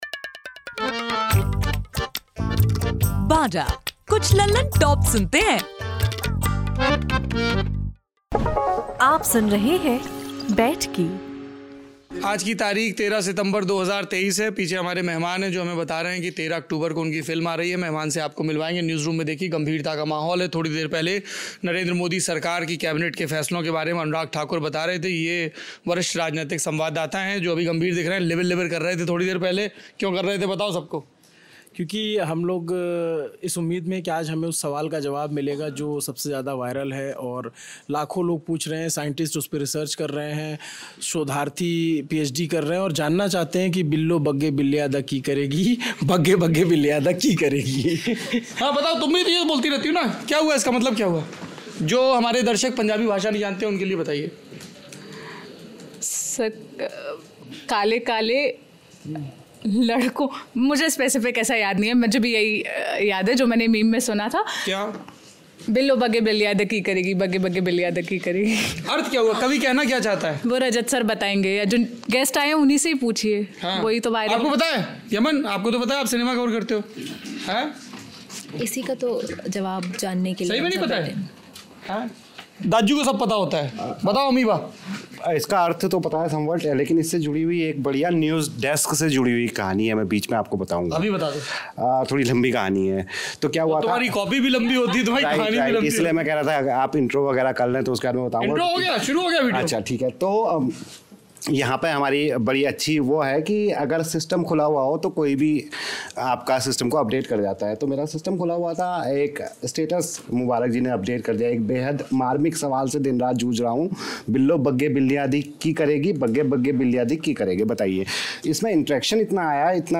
बैठकी के इस एपिसोड में आप सुनेंगे पंजाबी सिंगर और सांग राइटर काका को दी लल्लनटॉप की टीम और सौरभ द्विवेदी से बातचीत करते हुए. काका ने अपने फेमस गाने 'बिल्लो बग्गे बिल्लेयां दा की करेगी' के असल मतलब से लेकर इसको बनाने के पीछे की वजह पर भी बात की.